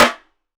SNARE.107.NEPT.wav